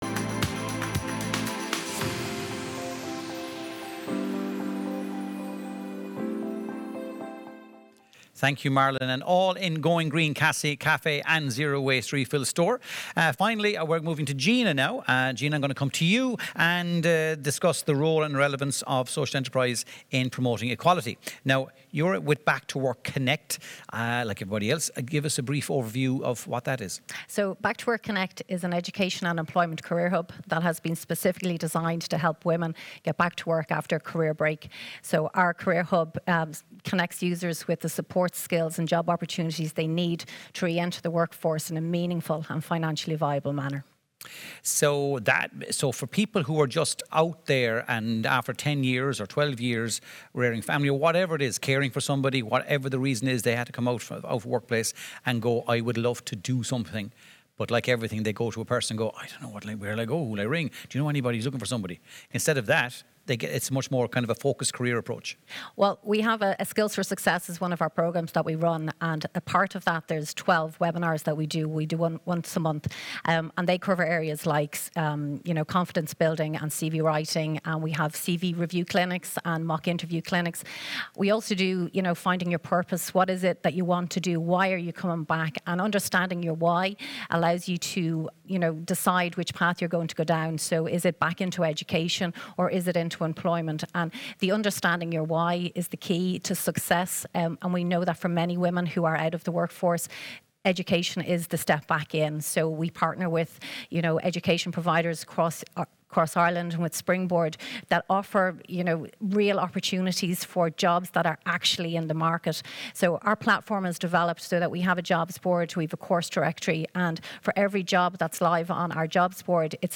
SED-Fund-Panel-Discussion_audio.aac